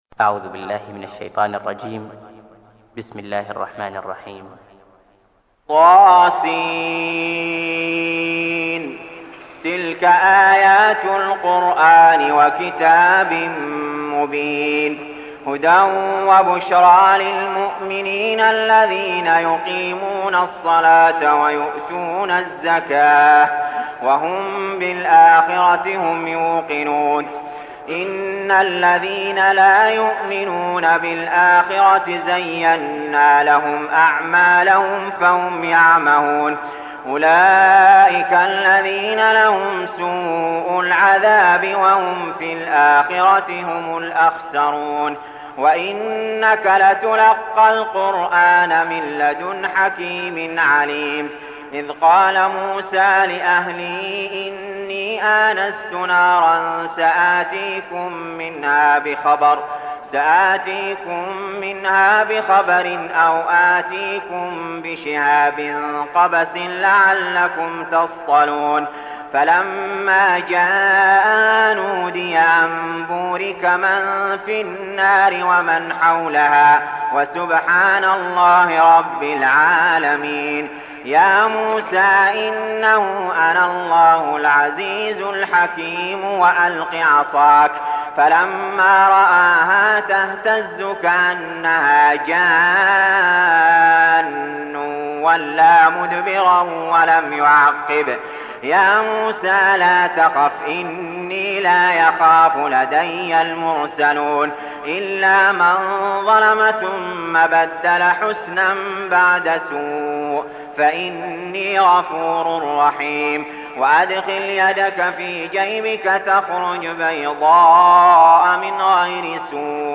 المكان: المسجد الحرام الشيخ: علي جابر رحمه الله علي جابر رحمه الله النمل